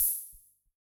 Index of /musicradar/retro-drum-machine-samples/Drums Hits/Raw
RDM_Raw_SR88-OpHat.wav